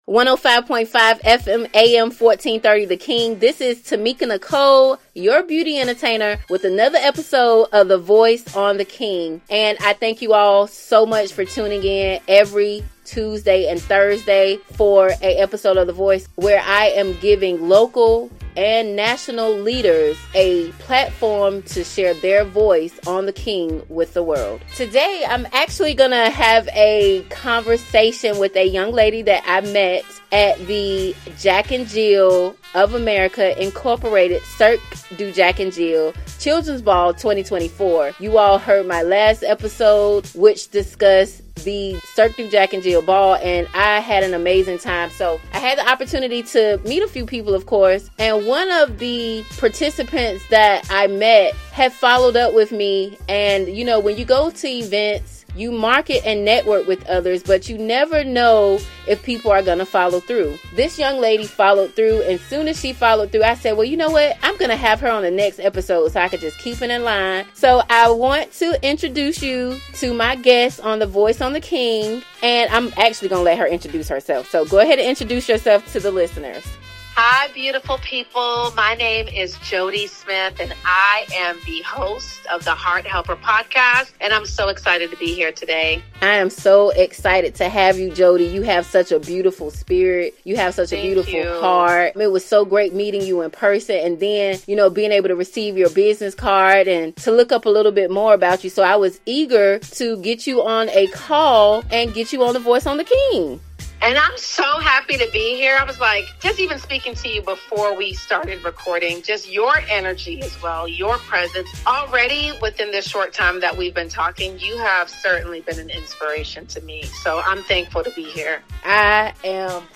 The Voice is a 2 Part Segment Show where local and national leaders share their stories with the world!
Heard on 105.5 FM/AM 1430 & AM 1010 The King